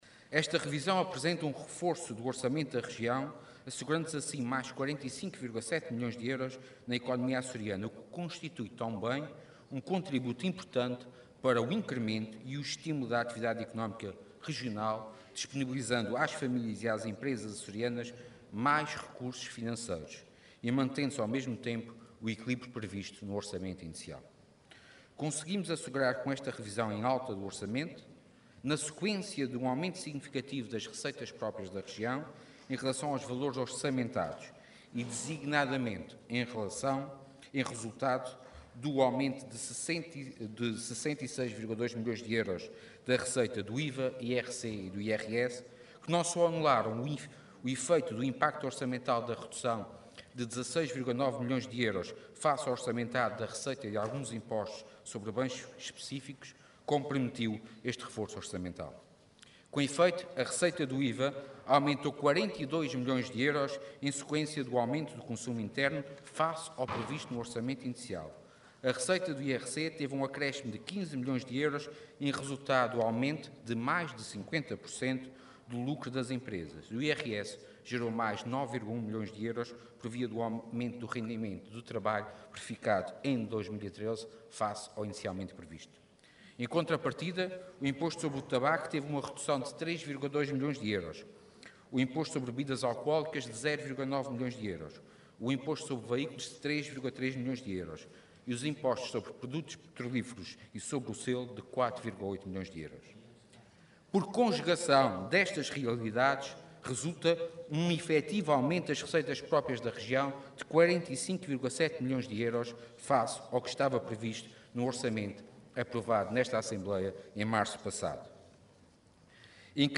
O Governo dos Açores apresentou hoje à Assembleia Legislativa o que o Vice-Presidente do Executivo qualificou de “revisão em alta” do Orçamento Regional para 2013.